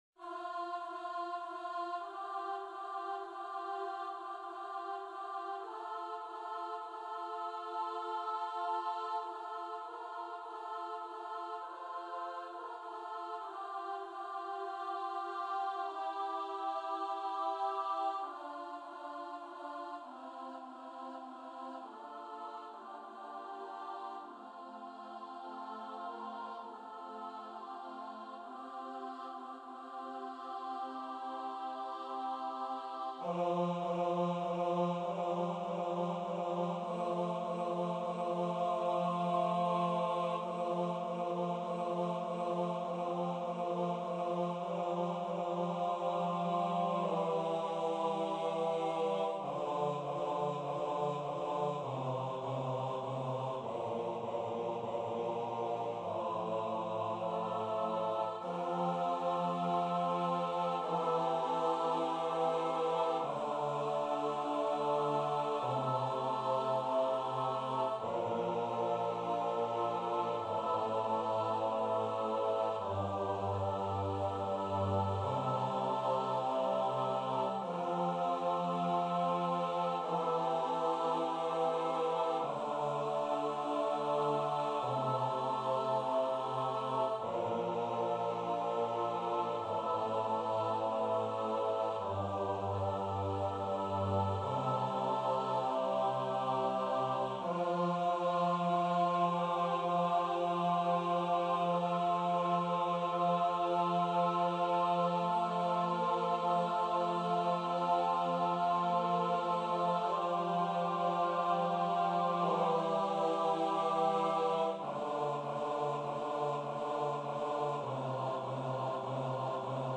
- Œuvre pour choeur à 8 voix mixtes (SSAATTBB) a capella
MP3 rendu voix synth.
Bass 2